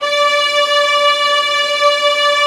VIOLINS.10-L.wav